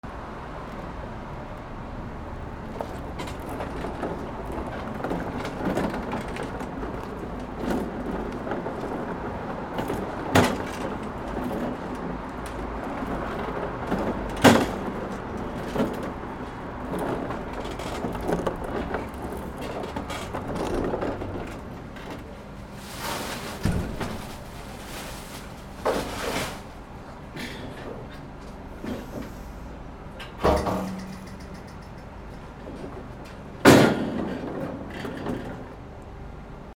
台車 動作音 回転
キャスター D50